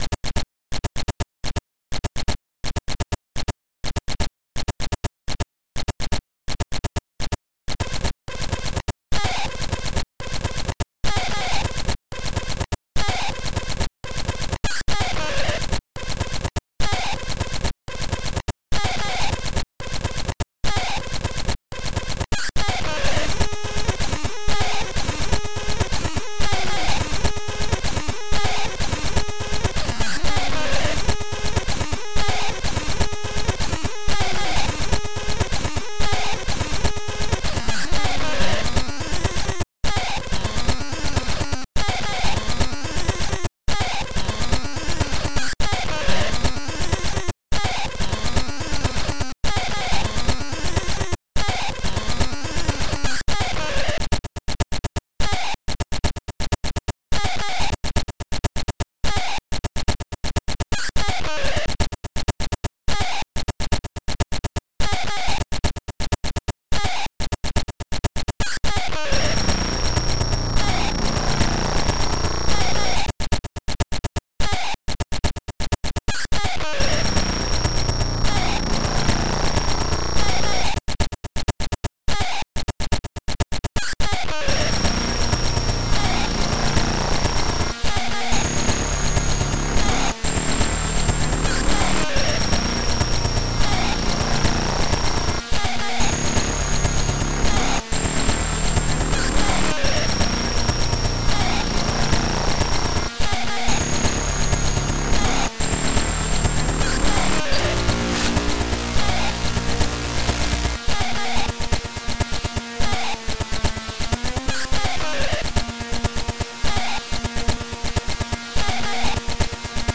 at techno, and